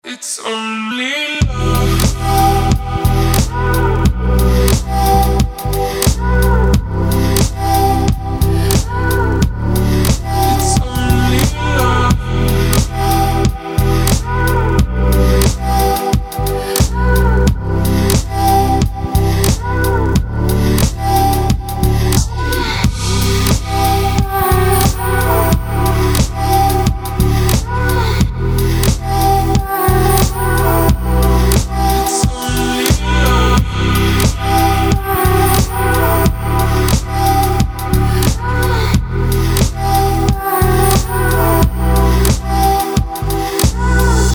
• Качество: 256, Stereo
Electronic
Bass
vocal
Очень красивая электронная музыка